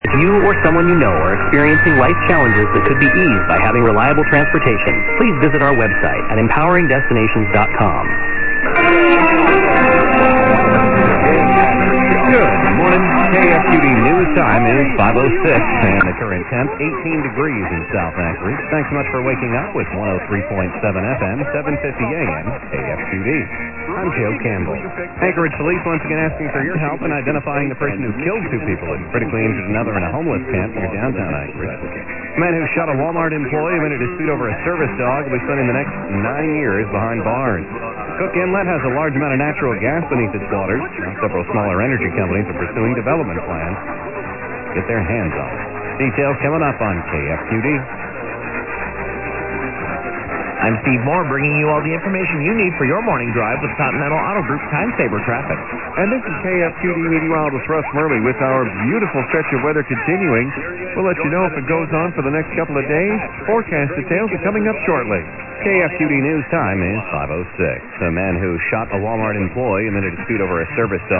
A few stations managed to put in good signals today.
Oh no!", etc 750 KFQD, jingle ID 1300 then CBS news mixing with KXTG. 1306 very dominant with local PSA, then AM/FM ID & local news.